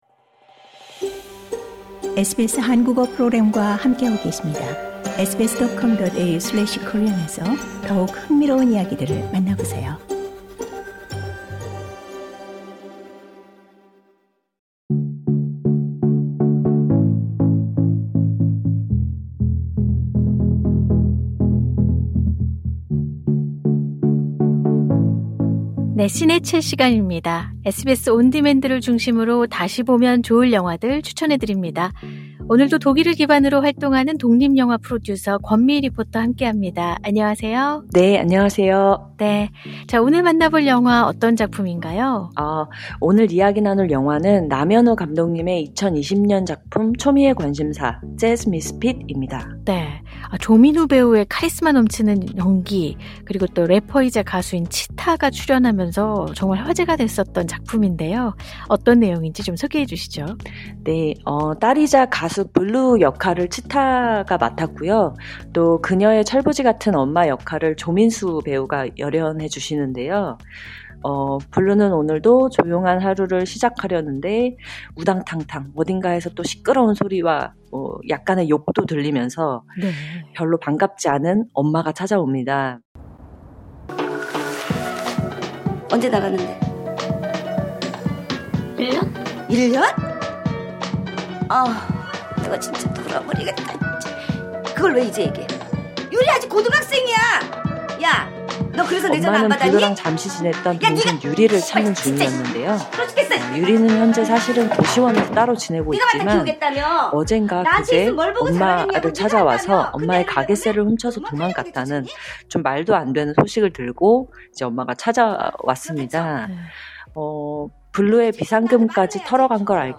Trailer Audio Clip 엄마는 블루랑 잠시 지냈던 동생 유리를 찾는 중이었는데요.